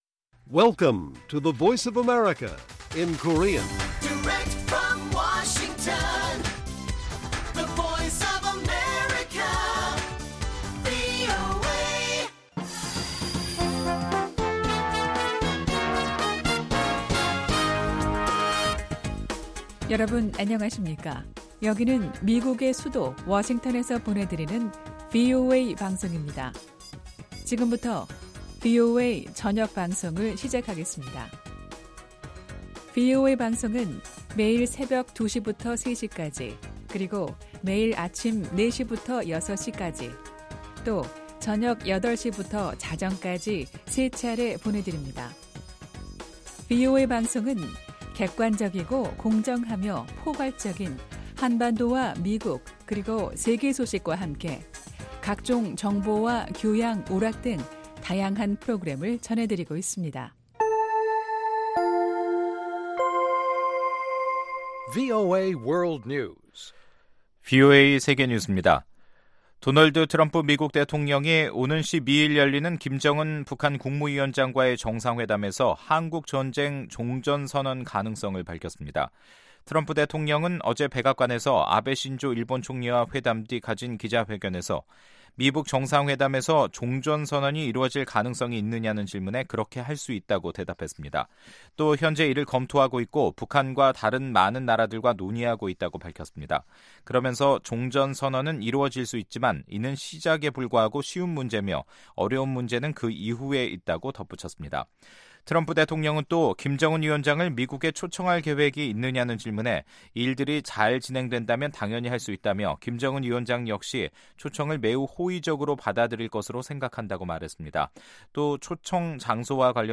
VOA 한국어 간판 뉴스 프로그램 '뉴스 투데이', 2018년 6월 8일 1부 방송입니다. 트럼프 대통령은 북한과의 정상회담을 앞두고 최대압박이라는 말을 안 하기로 했지만 협상이 실패할 경우 다시 그런 표현을 쓸 수 있다고 밝혔습니다. 마이크 폼페오 국무장관은 김정은 국무위원장이 비핵화할 준비가 돼 있음을 시사했다고 밝혔습니다.